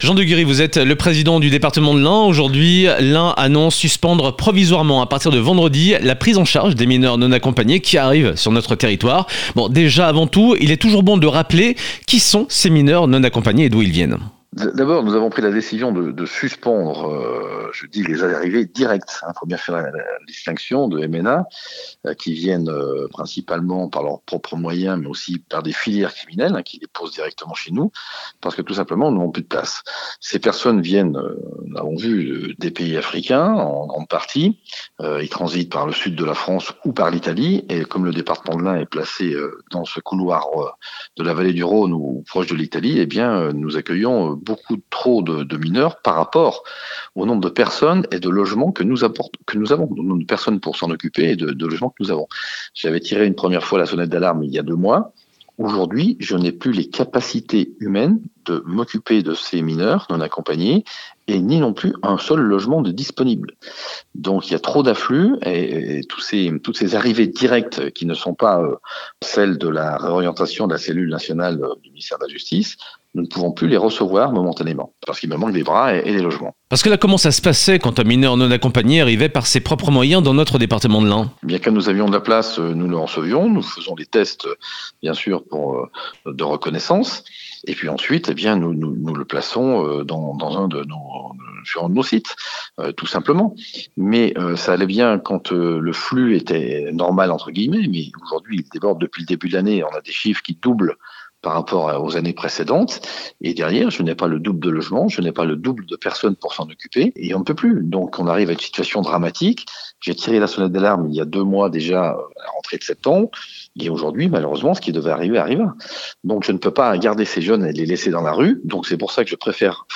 Écoutez Jean Deguerry, le président de l’Ain, très remonté et qui avait déjà tiré la sonnette d'alarme en septembre.